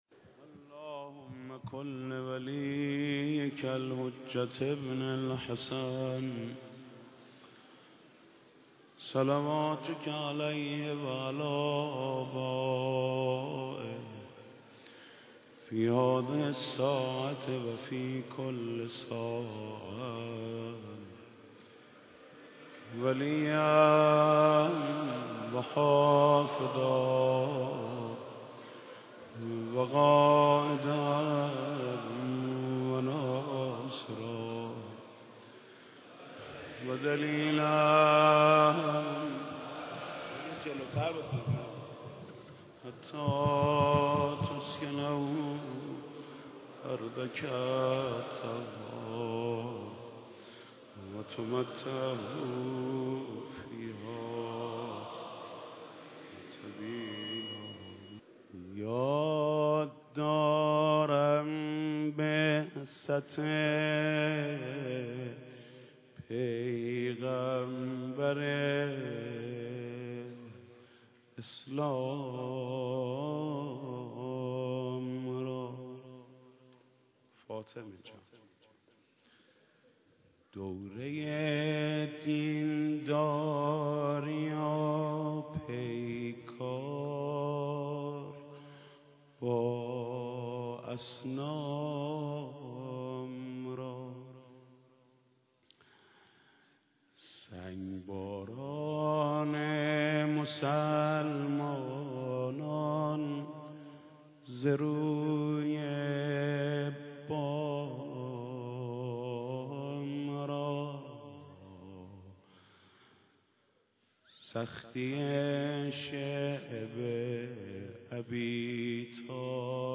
سخنرانی حجت الاسلام خاتمی و مداحی حاج محمود کریمی در بیت رهبری
شب سوم فاطمیه